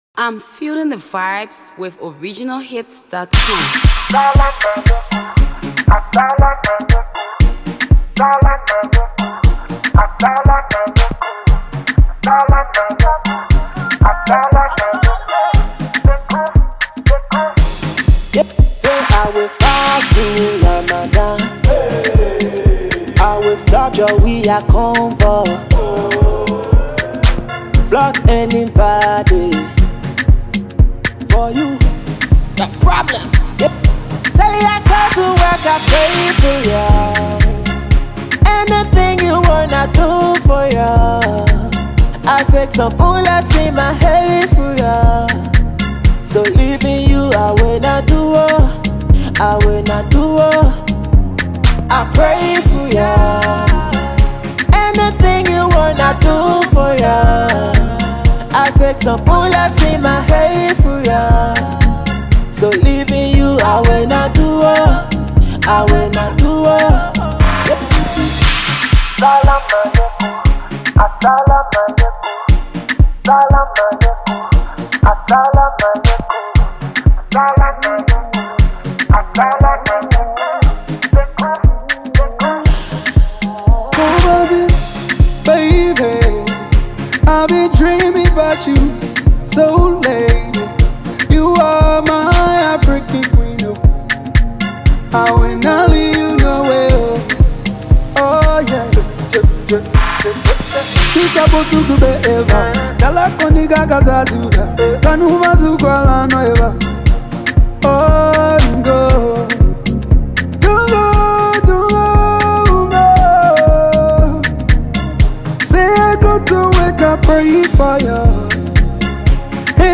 A hot banger .
Afro Pop